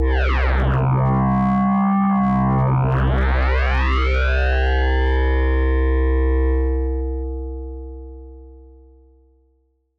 波形が変化するシンセサイザーの音シンセ音のスペクトログラム:音がウネウネと動くので、不規則に変化し、結果的に妖怪の顔のような形が浮かび上がったりする